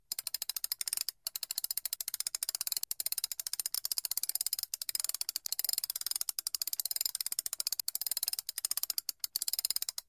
Мелкий механизм крутится